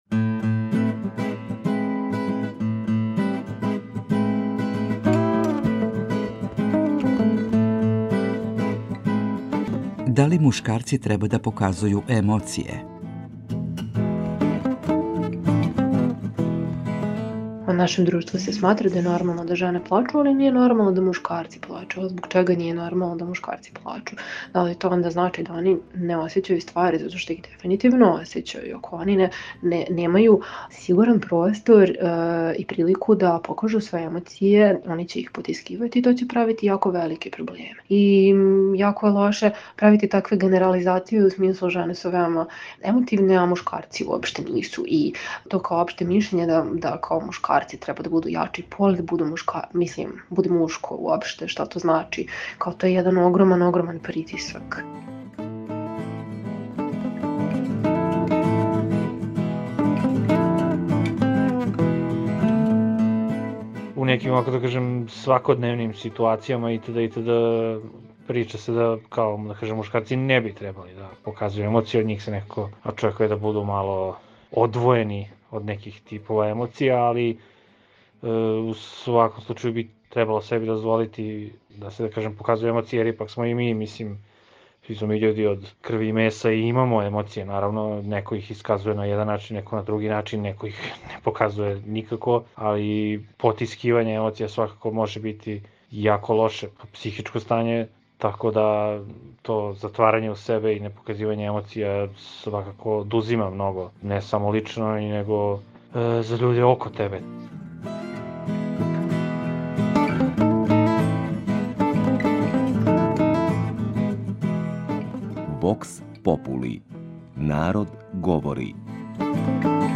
У данашњој емисији наши суграђани поделиће своје ставове са слушаоцима Другог програма Радио Београда. А питање на које ће одговарати гласи: Да ли мушкарци треба да показују емоције?
Вокс попули